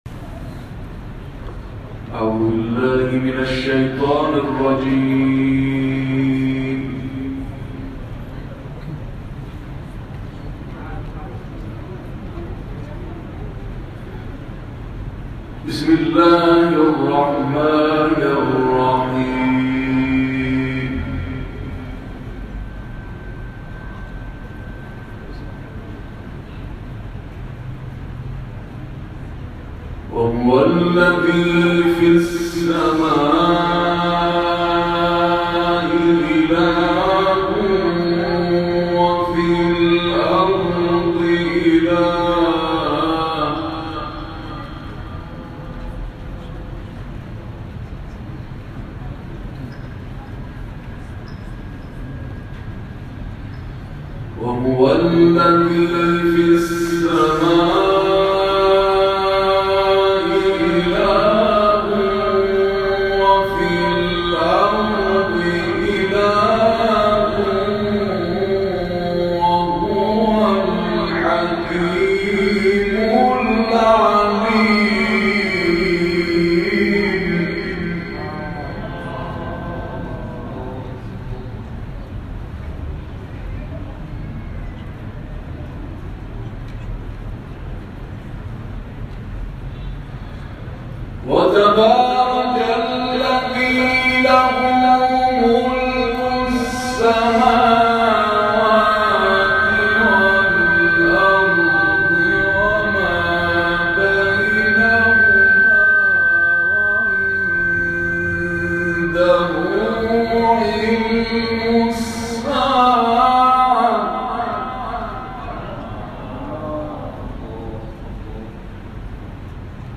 گروه فعالیت‌های قرآنی: محافل انس با قرآن کریم، شب گذشته هفتم تیرماه در شهرستان‌های فلاورجان و شهرستان گز در استان اصفهان برگزار شد.